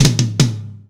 ROOM TOM2C.wav